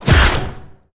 Sfx_creature_squidshark_flinch_01.ogg